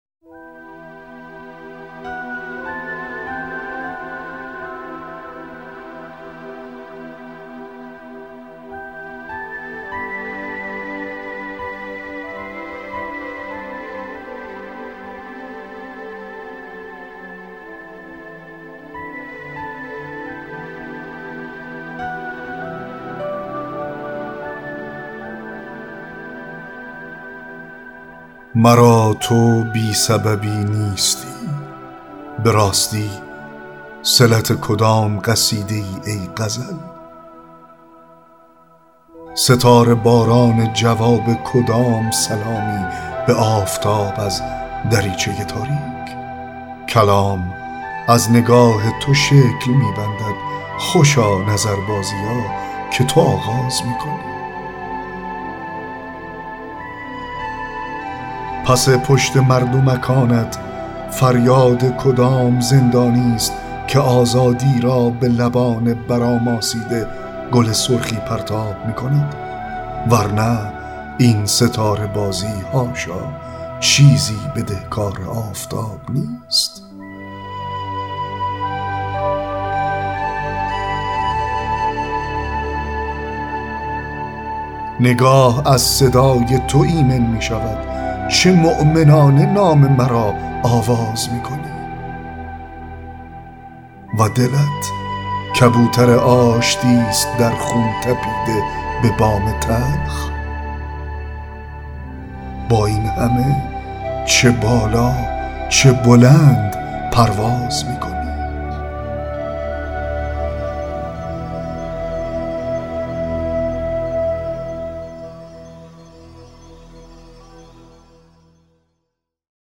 2- دکلمه شعر شبانه (مرا تو بی سببی نیستی…)